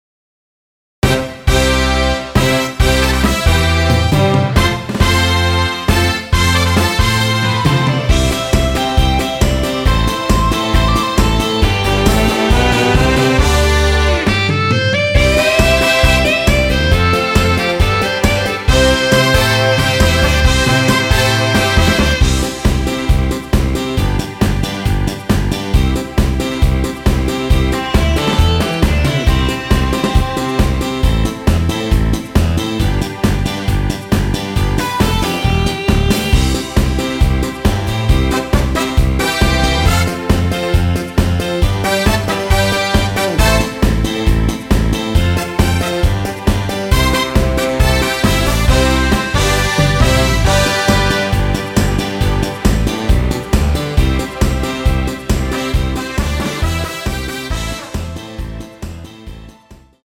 원키에서(+2)올린 MR입니다.
앞부분30초, 뒷부분30초씩 편집해서 올려 드리고 있습니다.
중간에 음이 끈어지고 다시 나오는 이유는